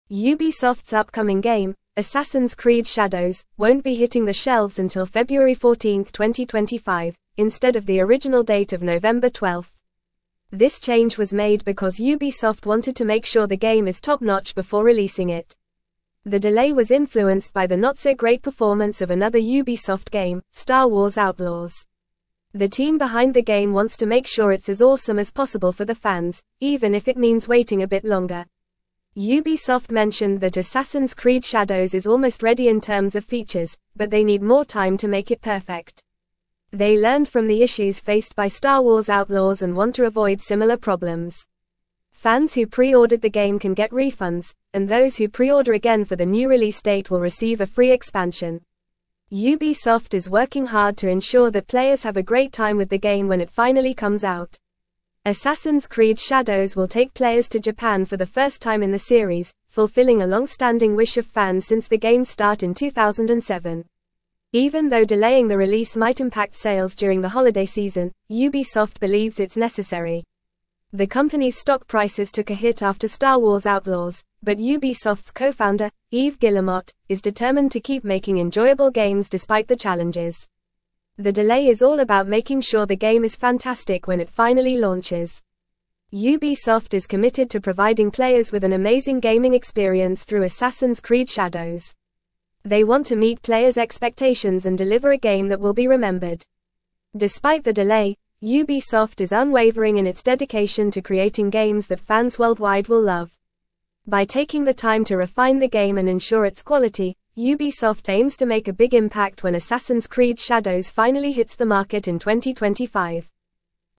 Fast
English-Newsroom-Upper-Intermediate-FAST-Reading-Ubisoft-Delays-Assassins-Creed-Shadows-Release-to-2025.mp3